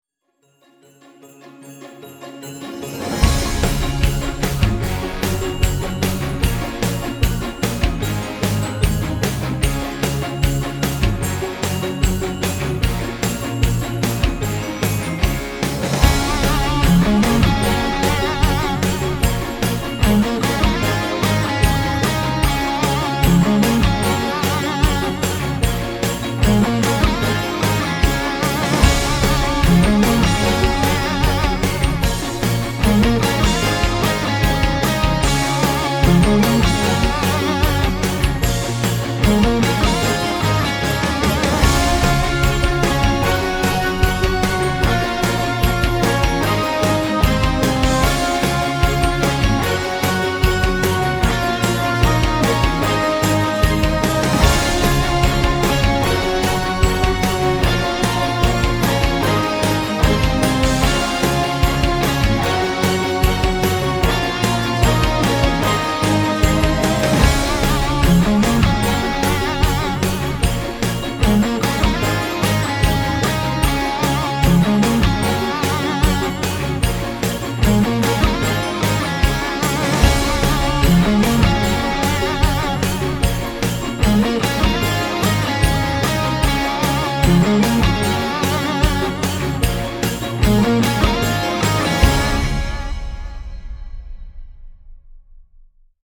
Tag: upbeat